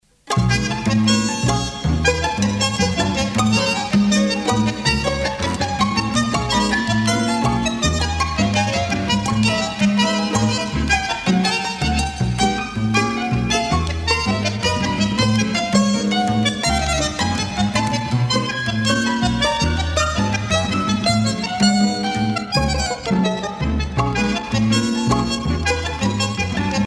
danse : tango (Argentine, Uruguay)
Pièce musicale éditée